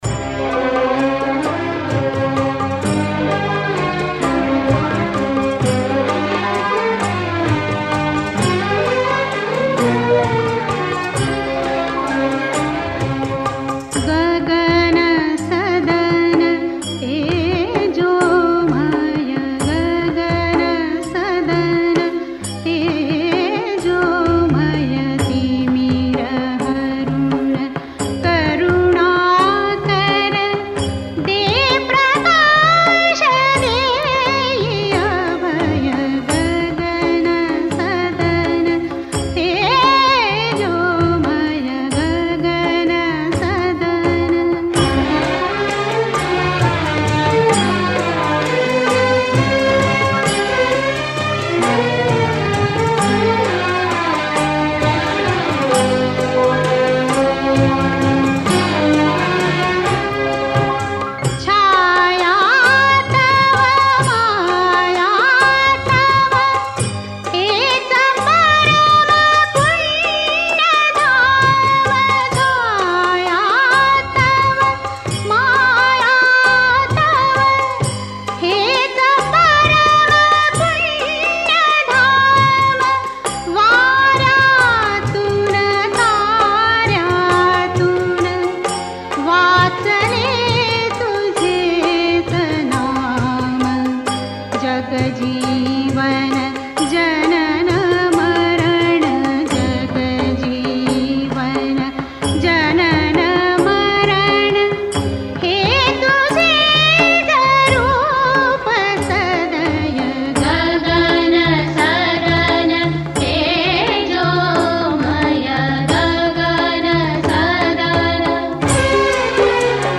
• Speaker: Singer